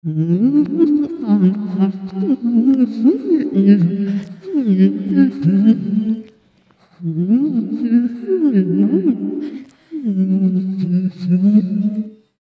Gemafreie Voices
mf_SE-3445-voice_fx_6.mp3